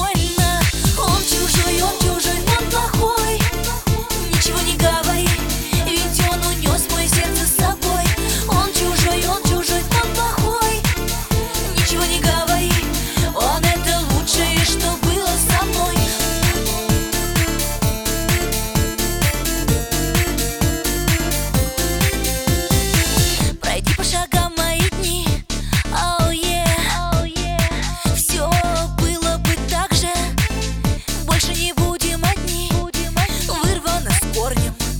Жанр: Поп музыка / Русский поп / Русские